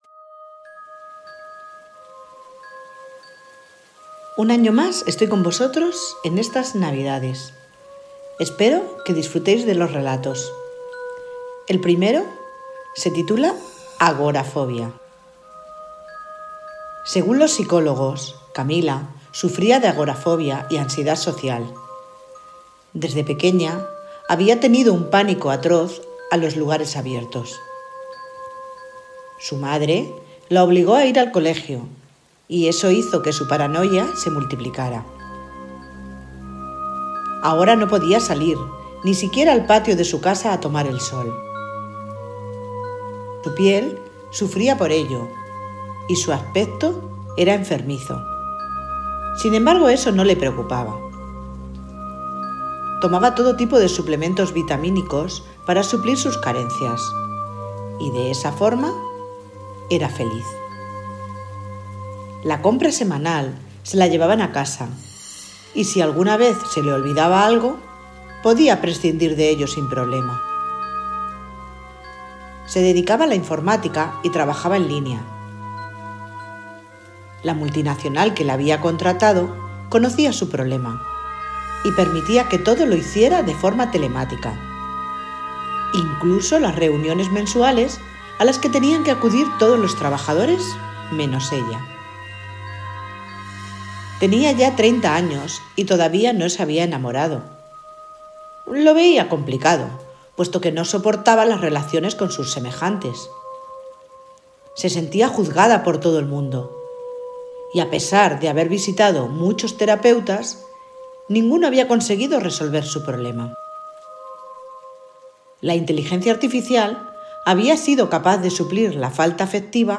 Ahora dale a la ilustración para escuchar el relato con mi voz, recuerda que alguna cosita siempre cambio.